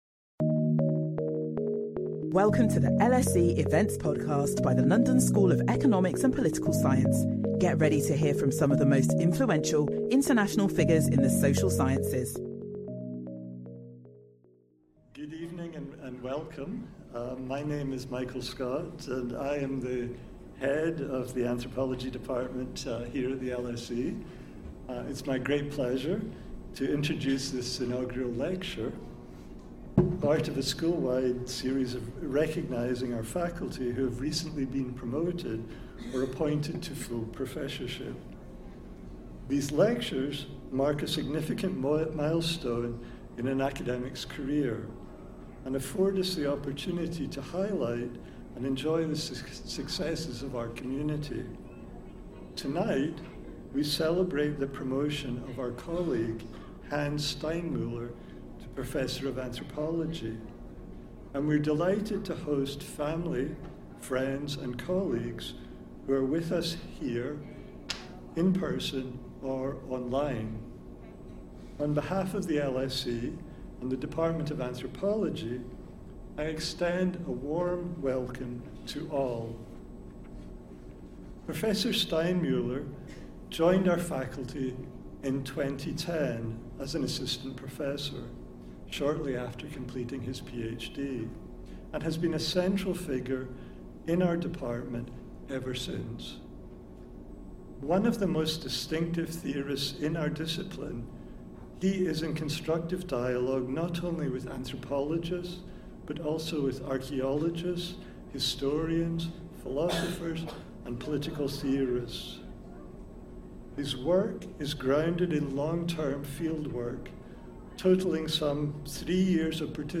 Public lectures and events